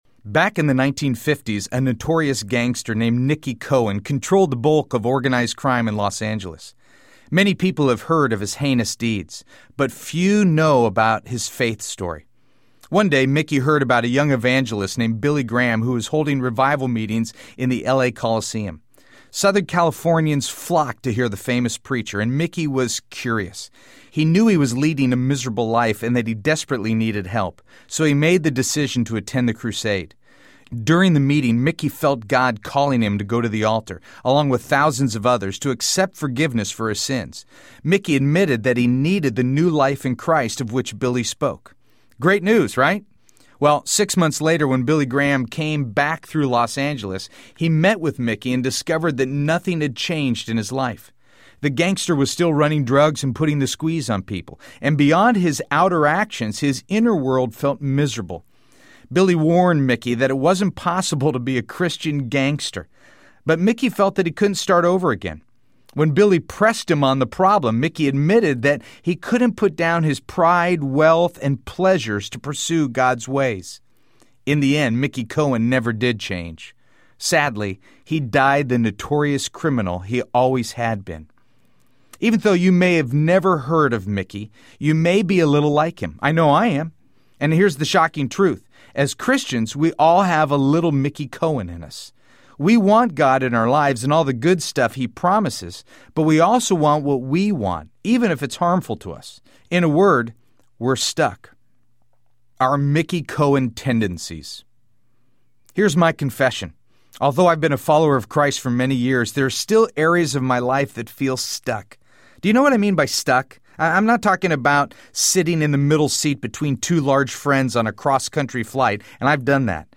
Fresh Start Audiobook
Narrator
6.0 Hrs. – Unabridged